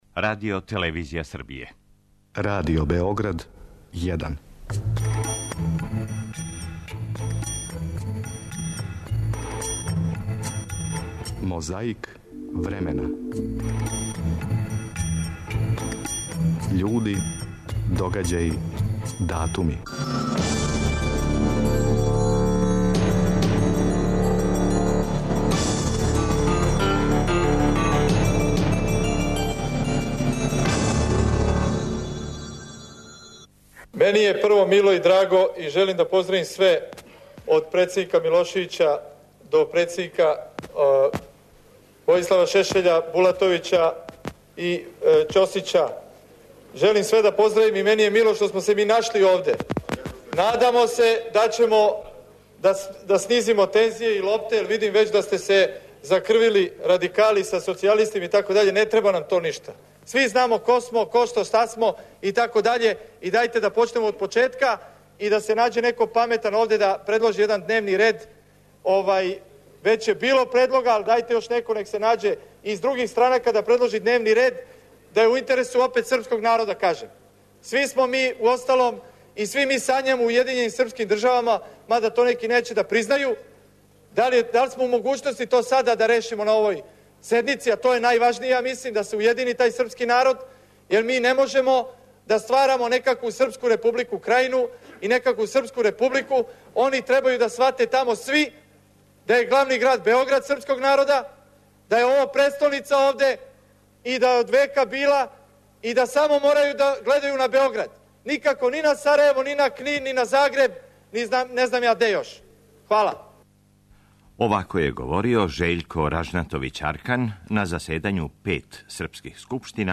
Емитоваћемо и говор патријарха Германа из 80-их година, на обележавању годишњице манастира Студеница.